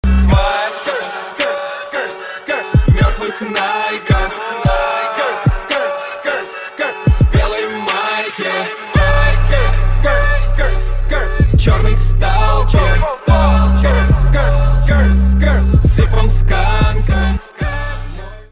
• Качество: 256, Stereo
русский рэп